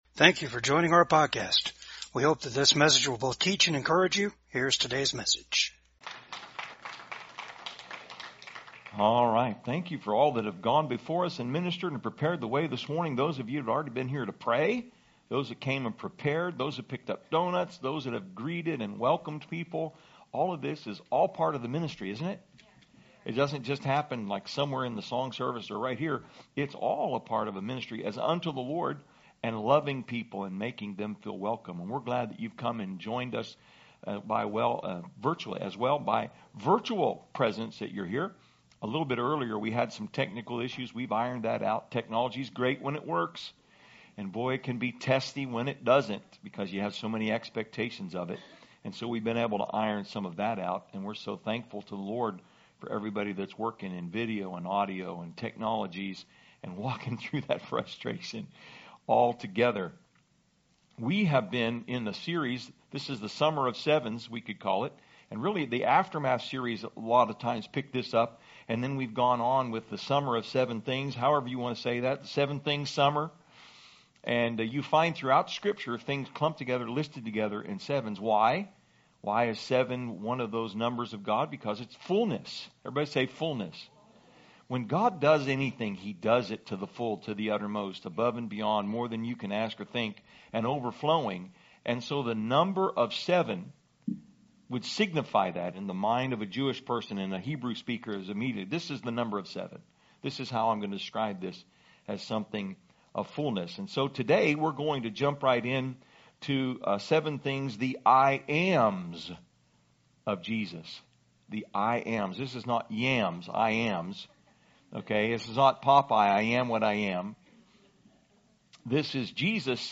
John Service Type: VCAG SUNDAY SERVICE 1.